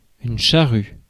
Ääntäminen
Synonyymit araire brabant gratte déneigeuse Ääntäminen France: IPA: [ʃa.ʁy] Tuntematon aksentti: IPA: /ʃaʀy/ Haettu sana löytyi näillä lähdekielillä: ranska Käännös Substantiivit 1. ader Suku: f .